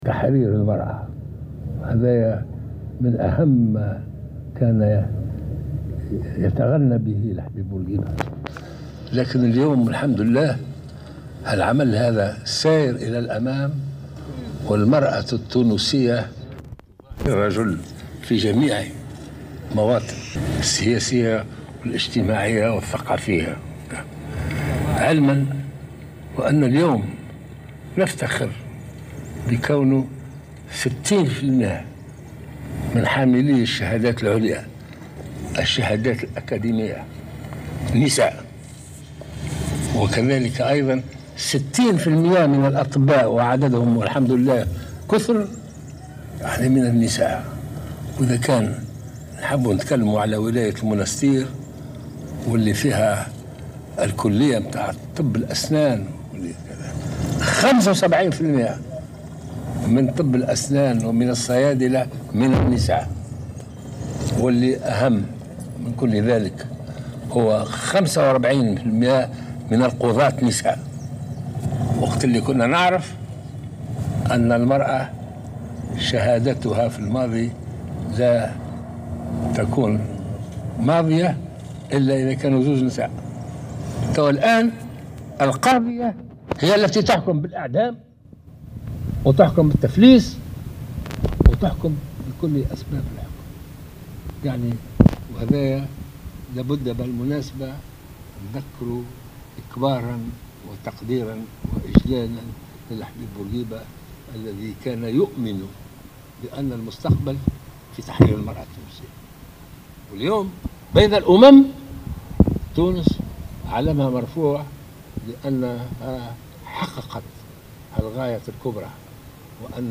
La femme tunisienne héritera bientôt sur un pied d'égalité avec l'homme, a lancé le président lors de son discours prononcé au Mausolée de Bourguiba.